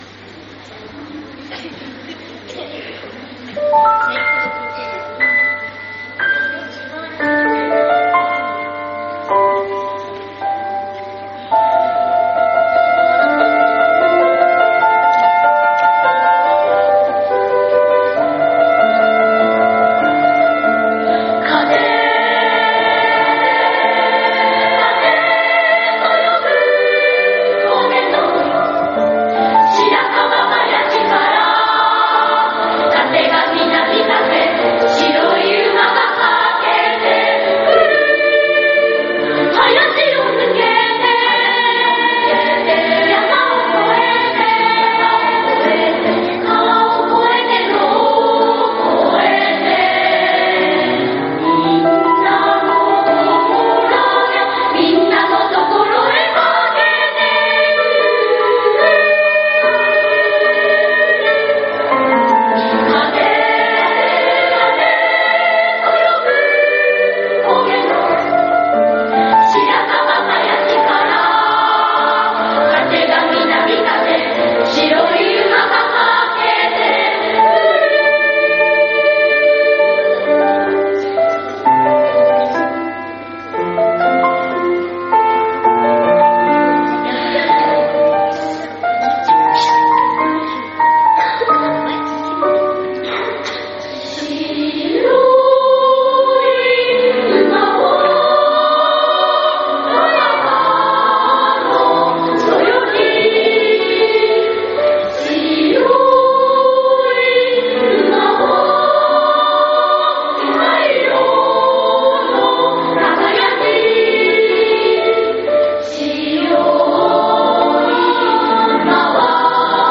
１０月５日（金）に体育館で行われた合唱部ミニ発表会の録音を紹介します。
本日昼休み、合唱部のミニ発表会が体育館で行われました。
きれいな歌声に全校児童もシーンとなって聞いていました。